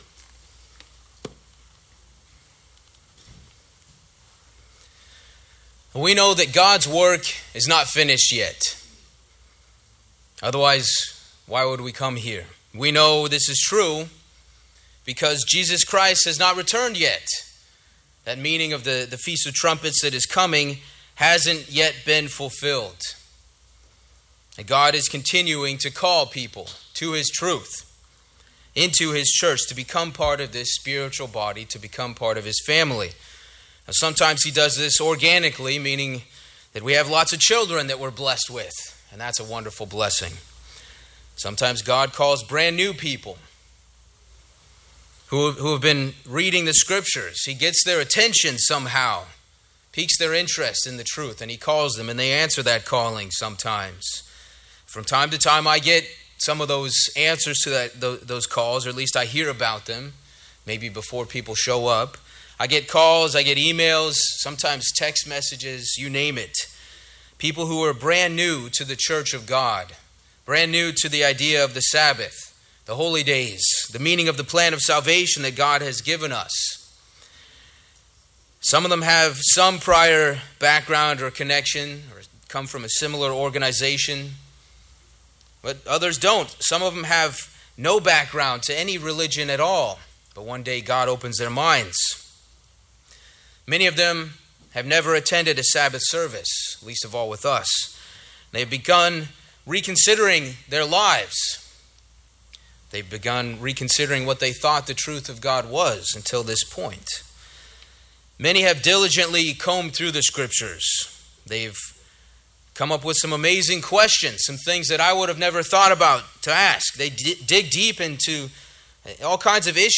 Given in New York City, NY